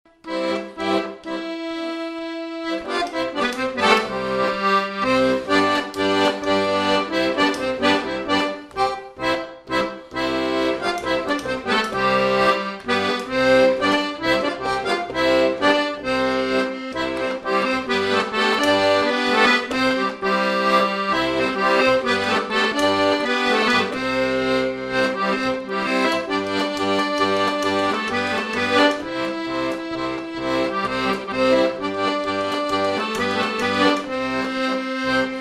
Polka
Résumé instrumental
danse : polka
Pièce musicale inédite